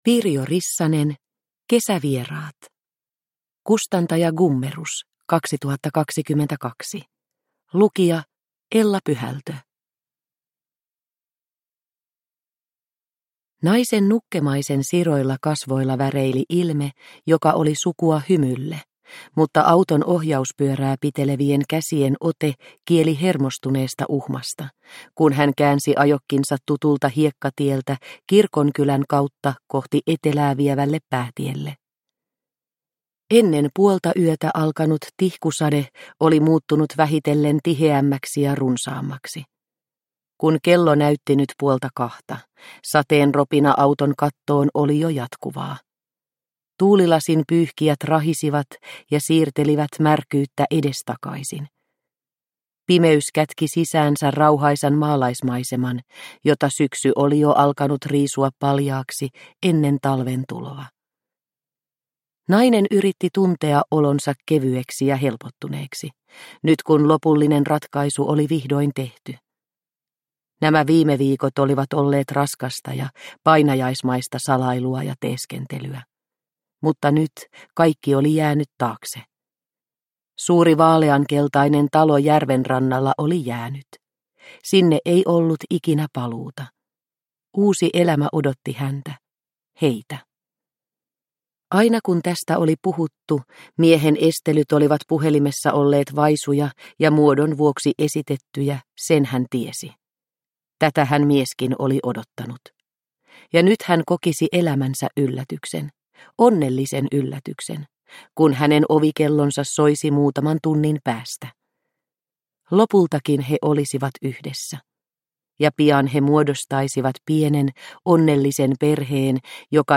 Kesävieraat – Ljudbok – Laddas ner